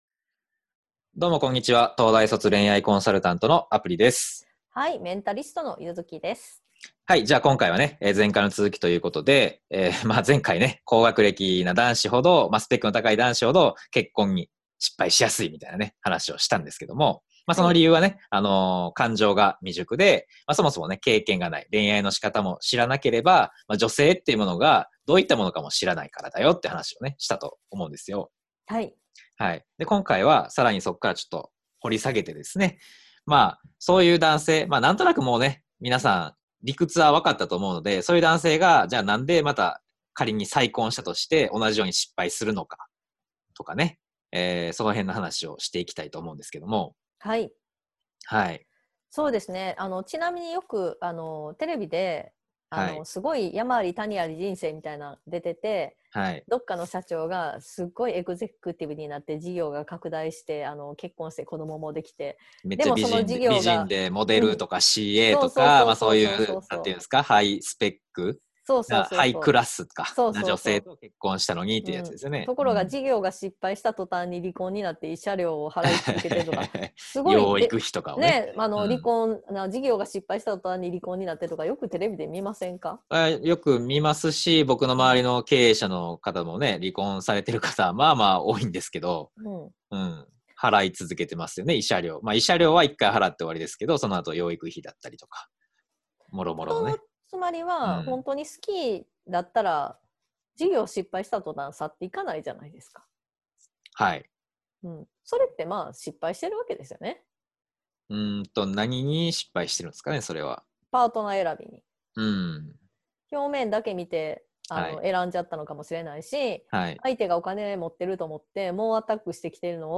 まずはこちらの対談音声をお聞きください。